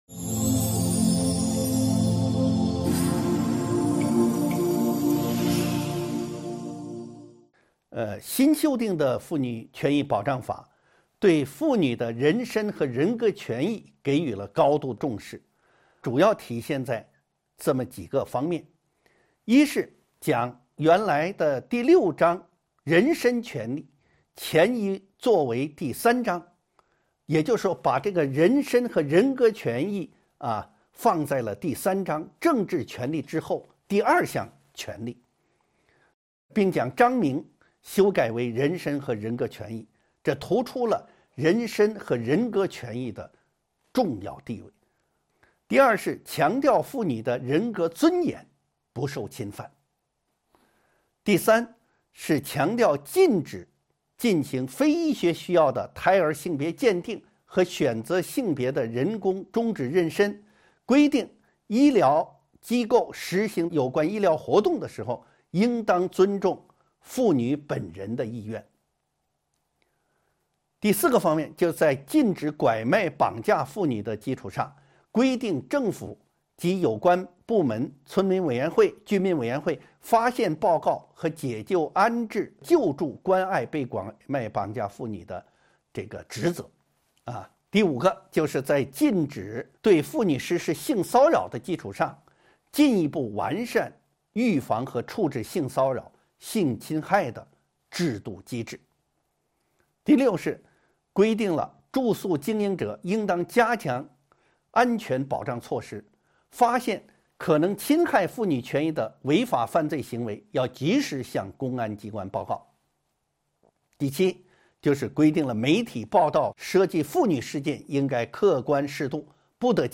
音频微课：《中华人民共和国妇女权益保障法》12.有效保障妇女人身安全